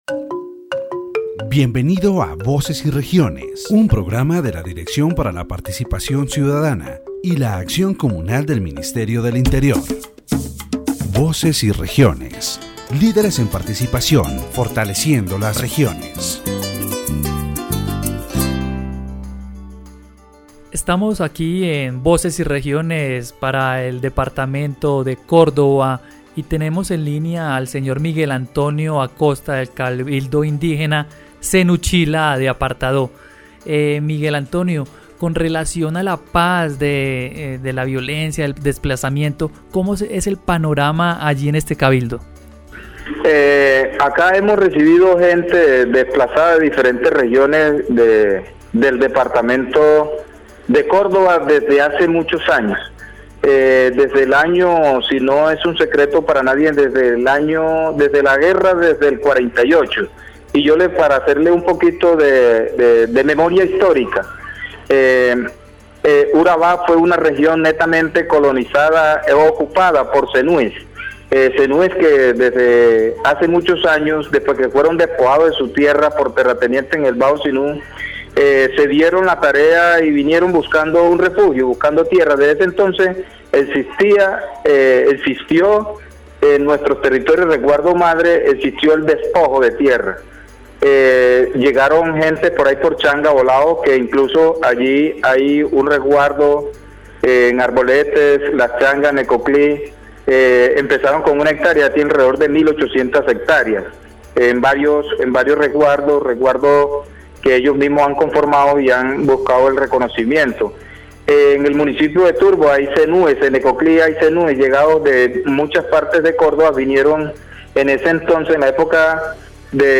In this section of Voces y Regiones, the interviewee reflects on the forced displacement experienced by the Zenú people, an Indigenous community with deep roots in Colombia's Caribbean region. He explains how violence and armed conflict have led to internal migration, which in many cases has resulted in the dispossession of ancestral lands belonging to Indigenous peoples.